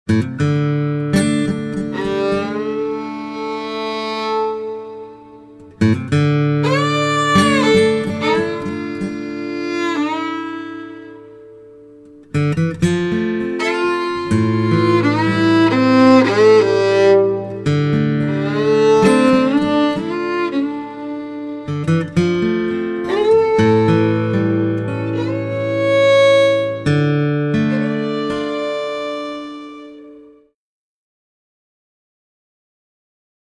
ROOTS MUSIC